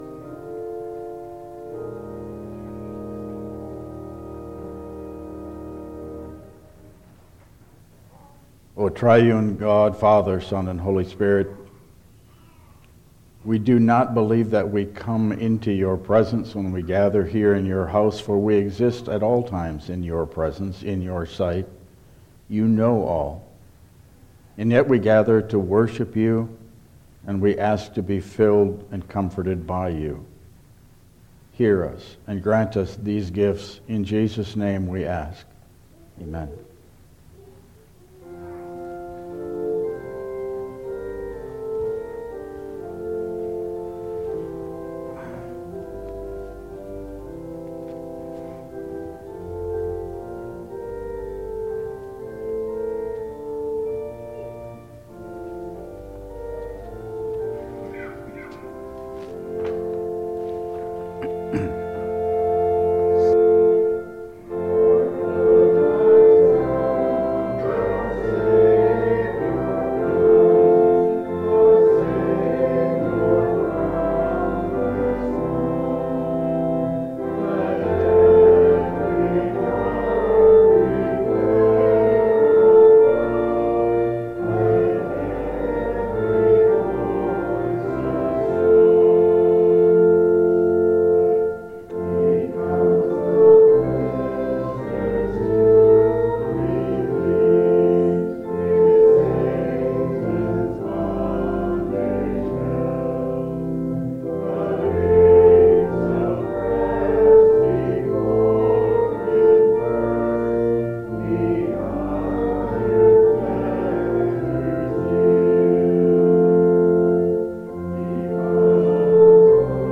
Passage: Isaiah 11:1-10 Service Type: Regular Service